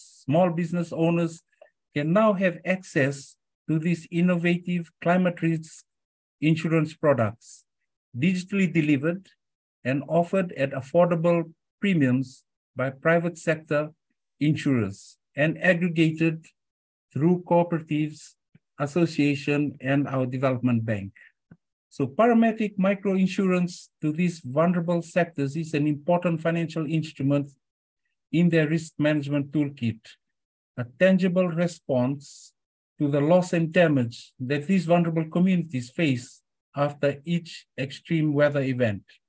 Speaking during the Climate Risk Insurance Solutions webinar, Reserve Bank’s Deputy Governor Esala Masitabua says the government offers VAT exemption on paramedic insurance premiums, which is the first of its kind in the Pacific.